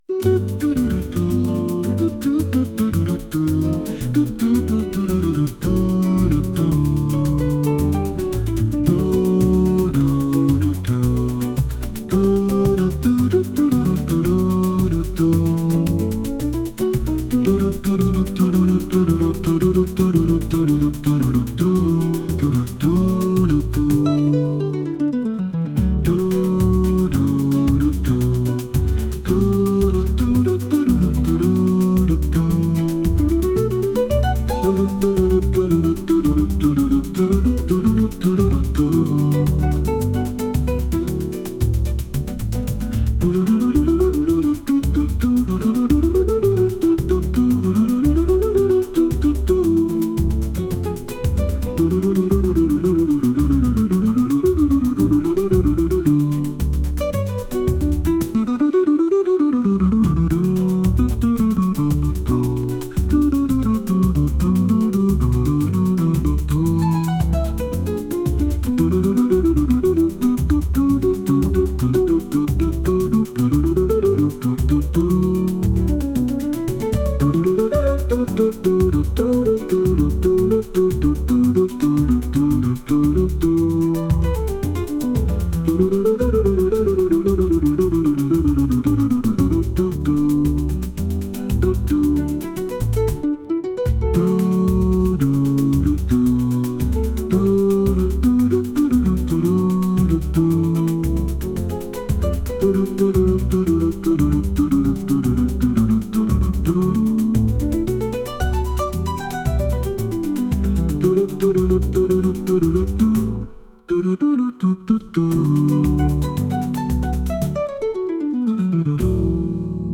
アカペラを含んだボサノバ曲です。